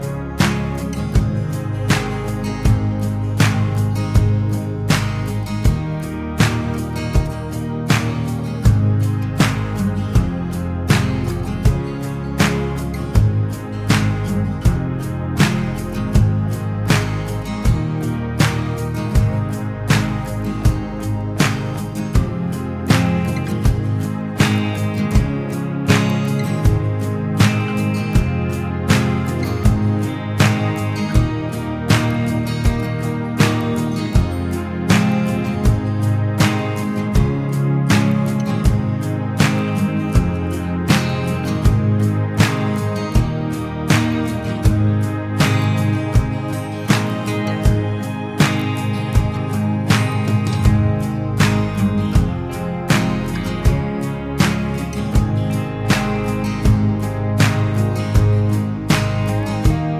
no Backing Vocals or Bass Pop (2010s) 4:04 Buy £1.50